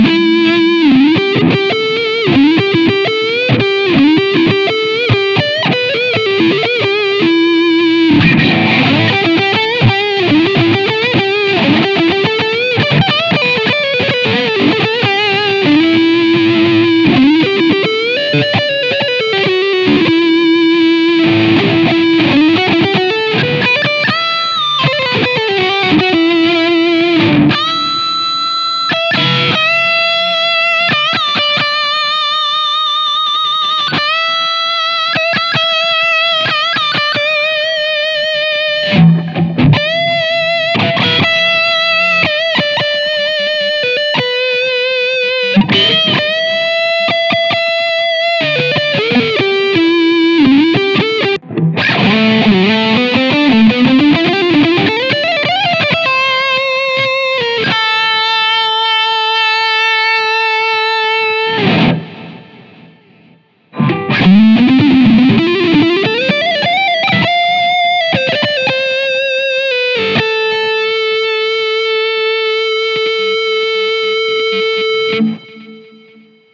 insane lead 2 1.56 MB
a longish clip. slightly modified version of InsaneLead. cab changed to 4x12 green 25s. also changed guitars, this is my ibanez s540, and i switch (often) between the neck and bridge humbuckers.
insanelead2.mp3